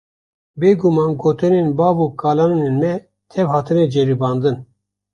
Tariamas kaip (IPA) /bɑːv/